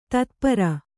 ♪ tatpara